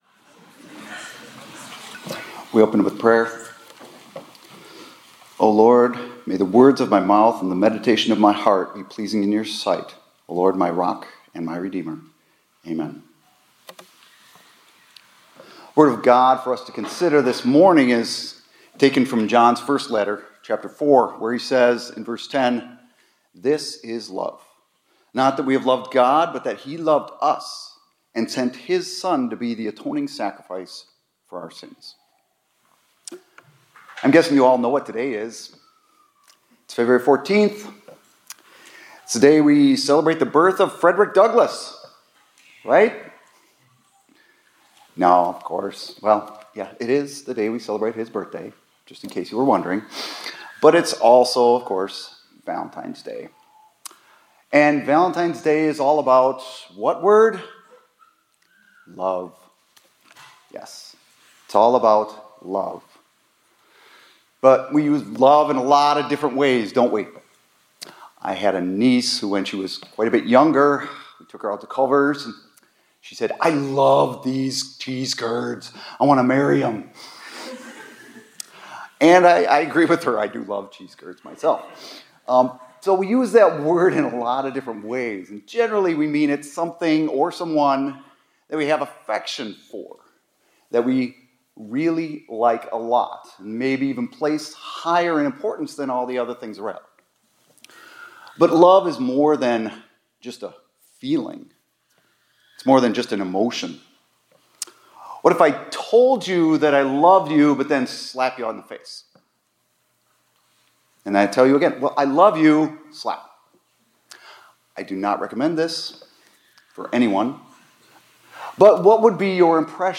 2025-02-14 ILC Chapel — Love is Action